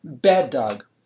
Examples with Downsteps